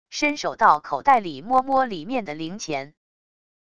伸手到口袋里摸摸里面的零钱wav音频